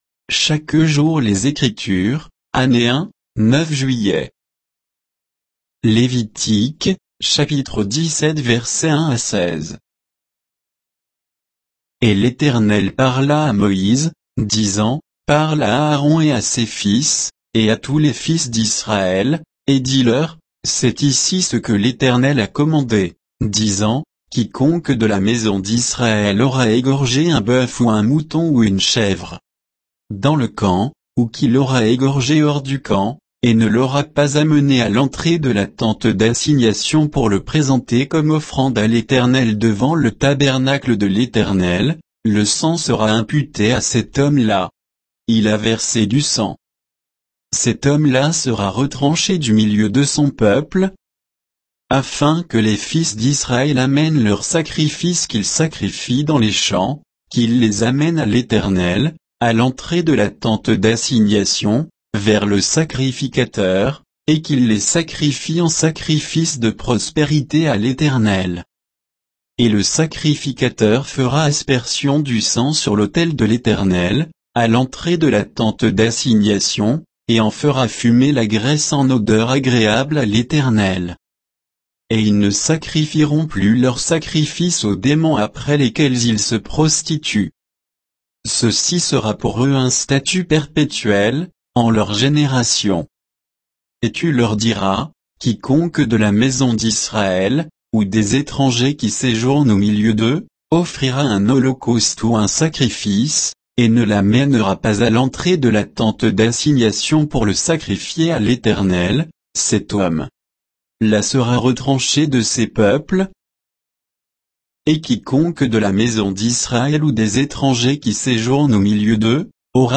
Méditation quoditienne de Chaque jour les Écritures sur Lévitique 17